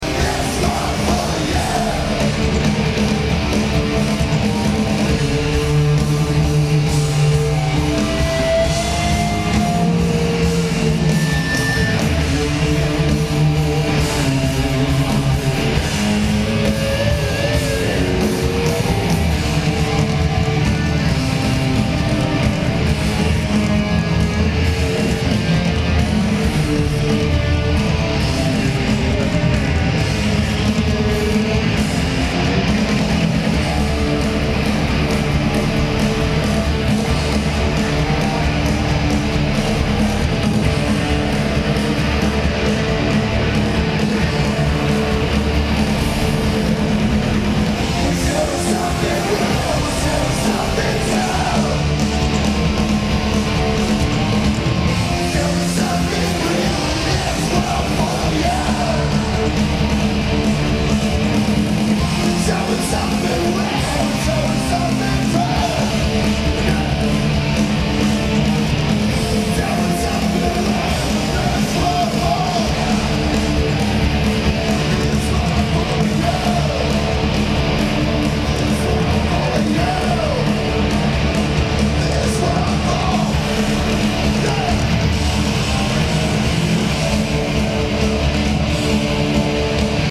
Comcast Center
Lineage: Audio - AUD (Olympus LS-10 Linear PCM Recorder)